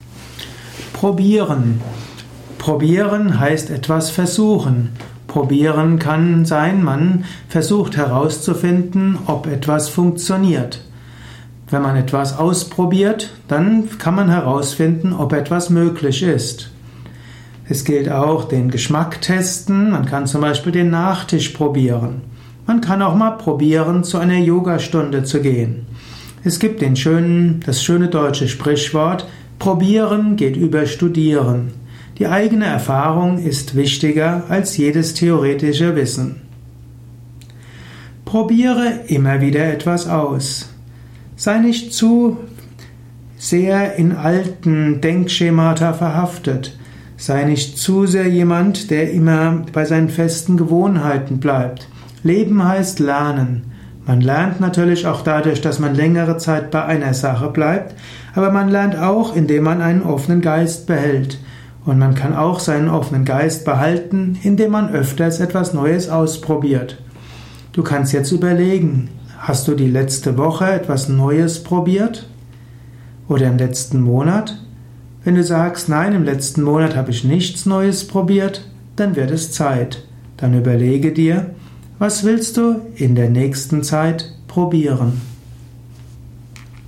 Abhandlung mit dem Gegenstand Probieren. Simple und Komplexe zum Informationen zum Thema Probieren durch dieses Vortragsaudio.